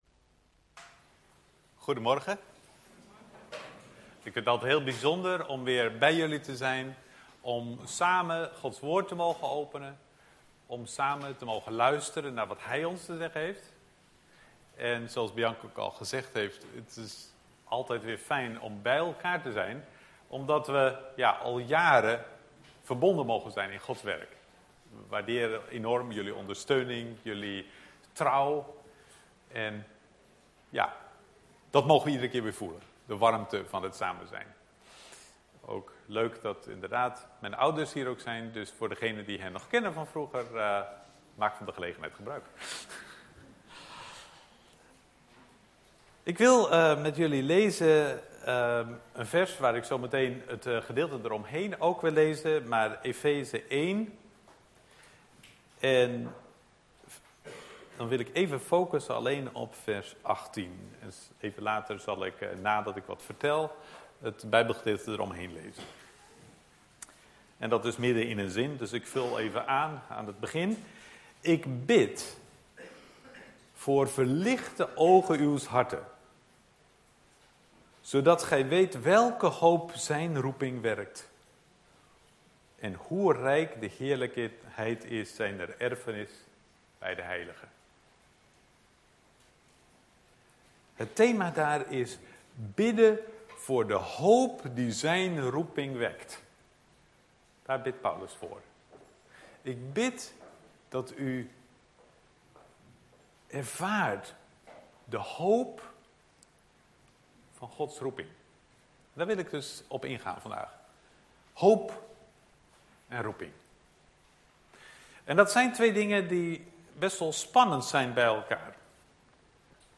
preek